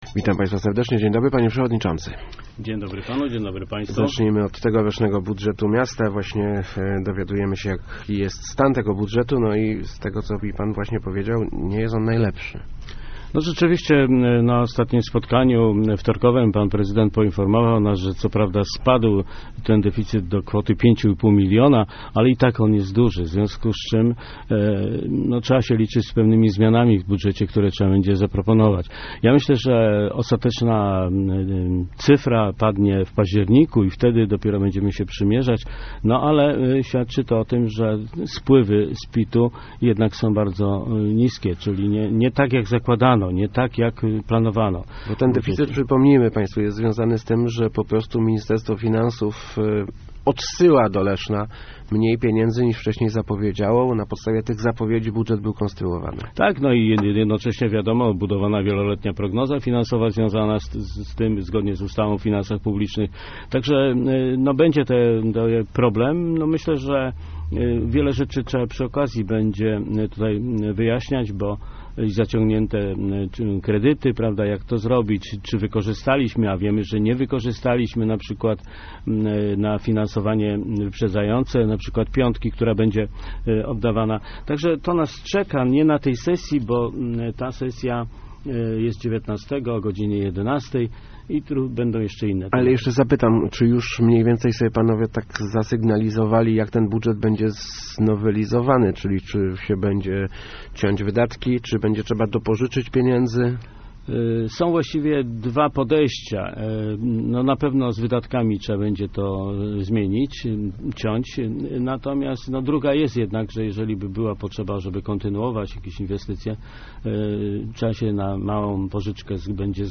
Gościem Kwadransa był Tadeusz Pawlaczyk, przewodniczący Rady Miejskiej Leszna ...